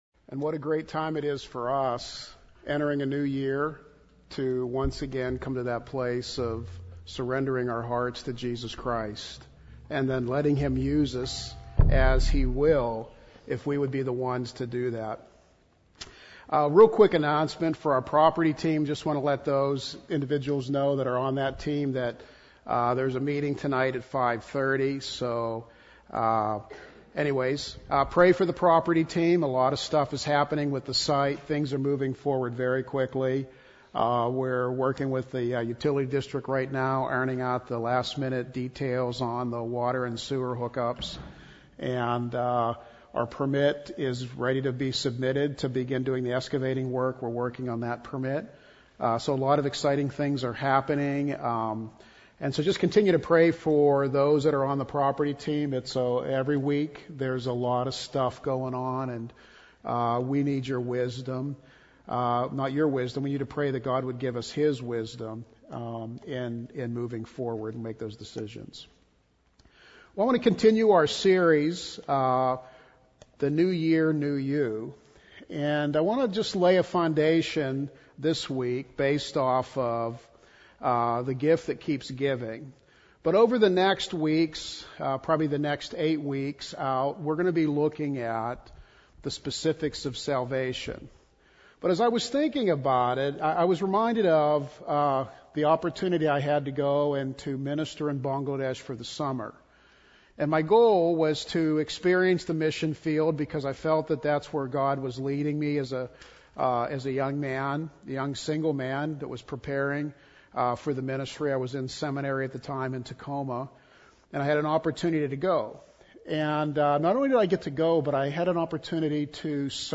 Service Type: Sunday AM Worship Service